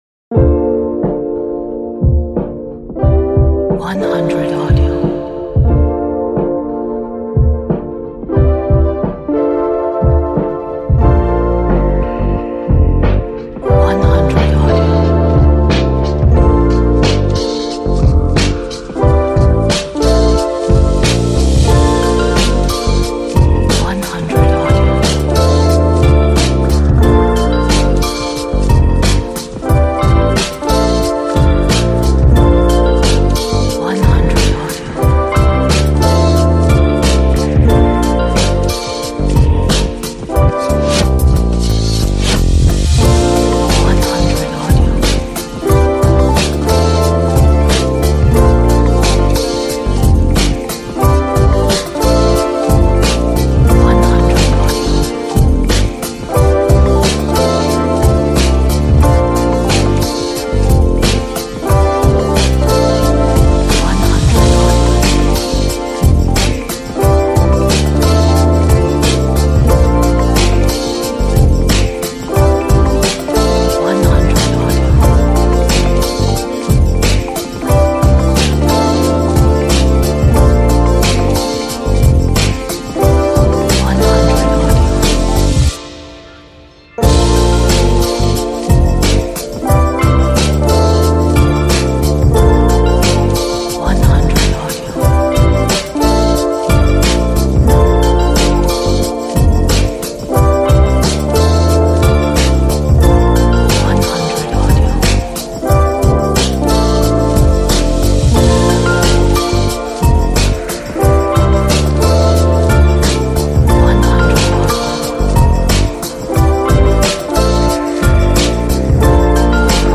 Chill-hop music.
一首Chill-hop音乐。